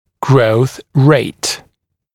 [grəuθ reɪt][гроус рэйт]скорость роста, степень скорости роста